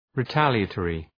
Shkrimi fonetik{rı’tælıə,tɔ:rı}